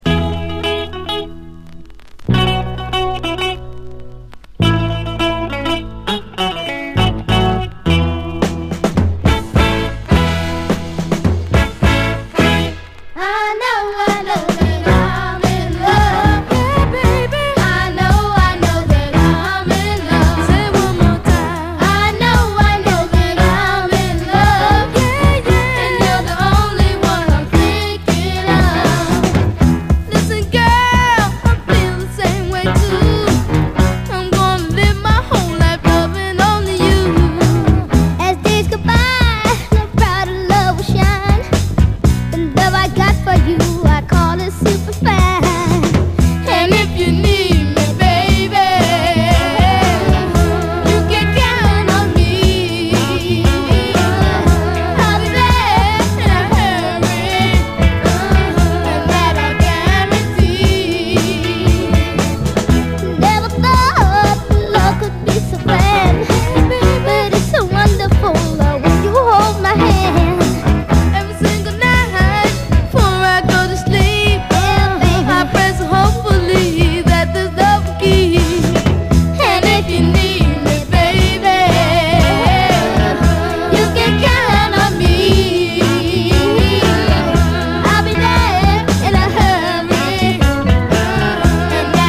SOUL, 70's～ SOUL, 7INCH
男の子＆女の子デュオによる、キュートで楽しいキッズ・ソウル！